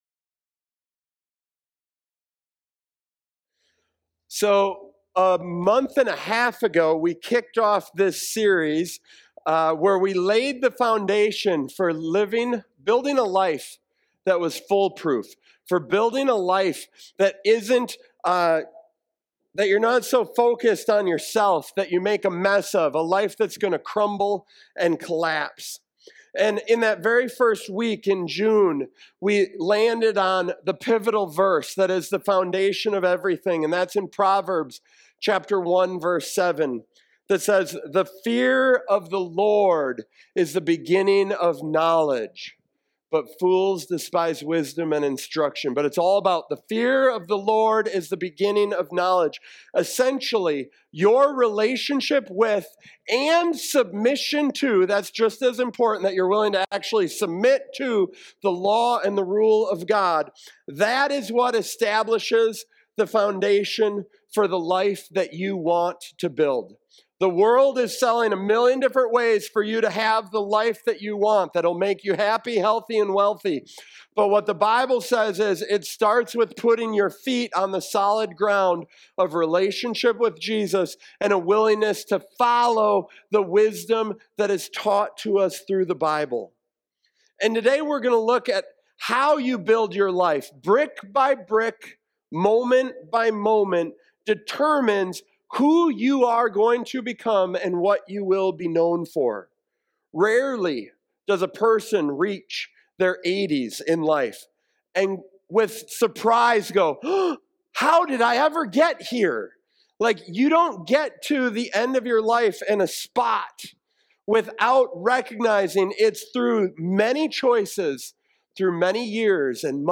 This sermon challenges us to build a fool-proof life through integrity, living with honesty and strong moral principles, rooted in the fear of the Lord (Proverbs 1:7). Integrity strengthens our lives with security, guidance, and protection, while dishonesty creates cracks in our character.